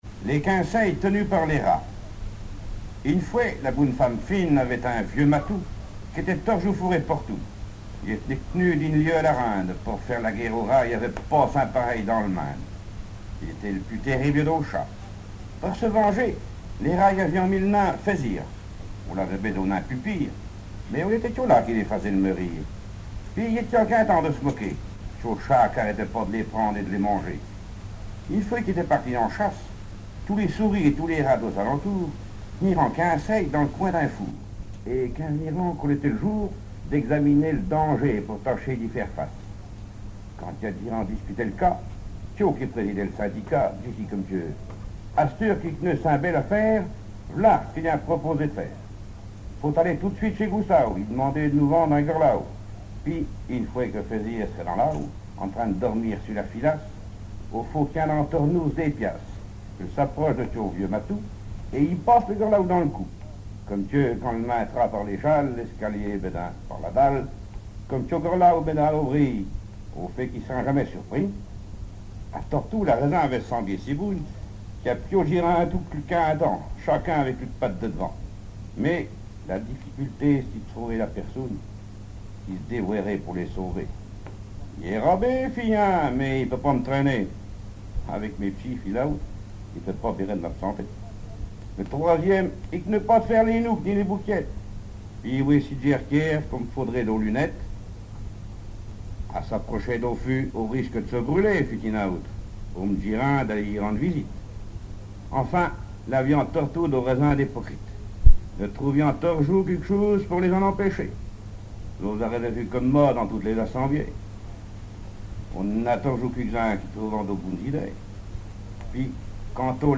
Genre fable
Catégorie Récit